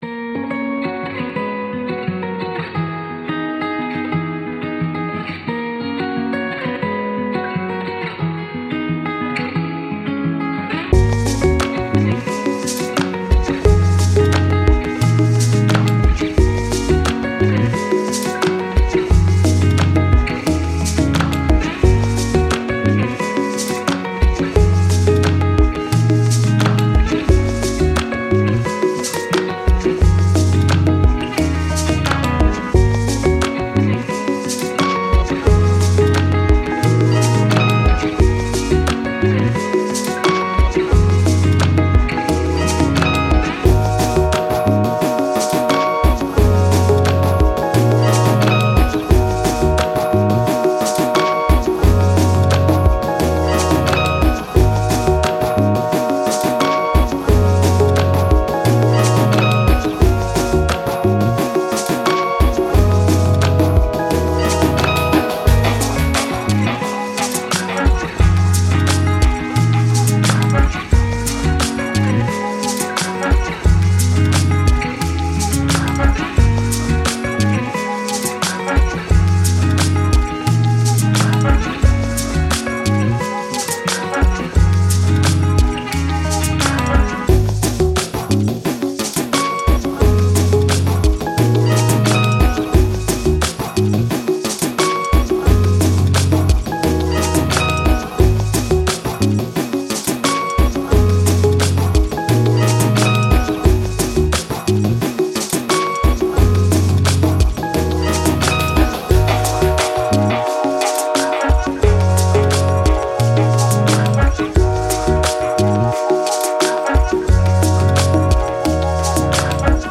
Ambient, Downtempo, Chill